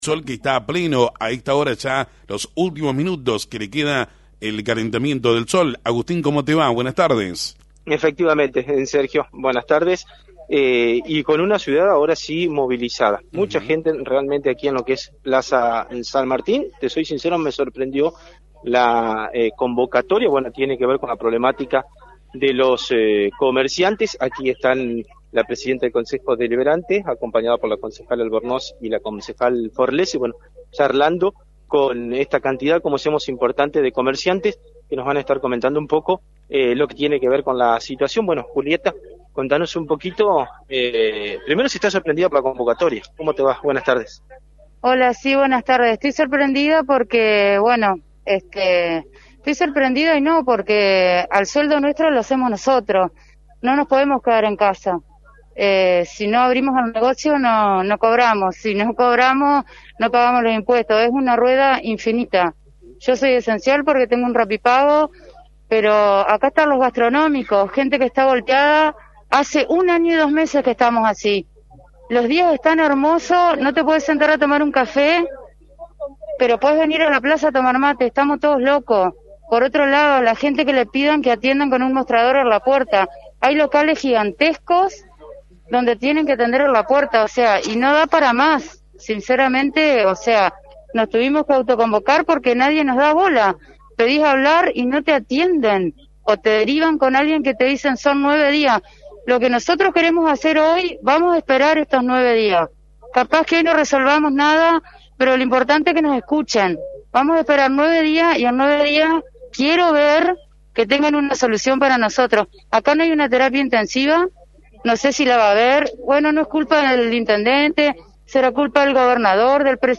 Móvil de LT39 en la convocatoria de comerciantes ante las restricciones – Lt39 Noticias